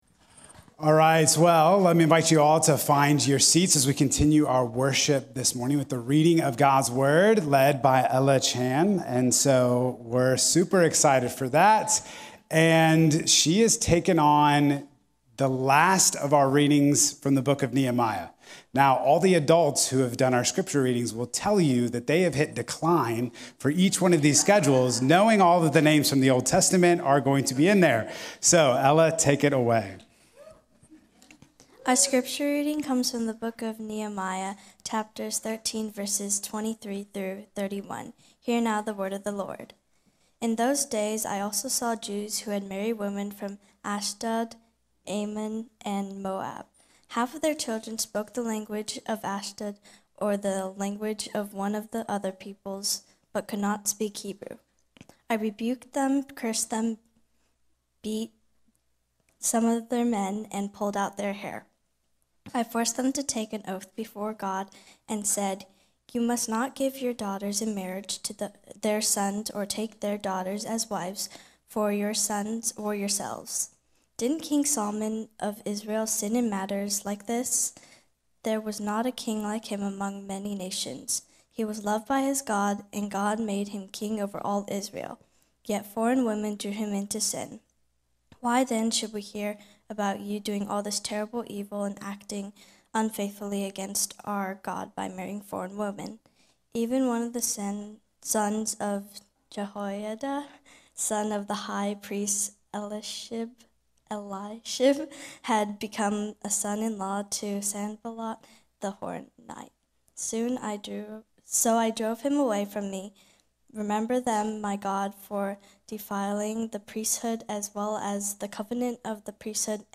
This sermon will give hope to the overwhelmed and the frustrated by reminding us that reaching your limit may not be the end—but the beginning of a deeper trust and renewed relationship with the Lord.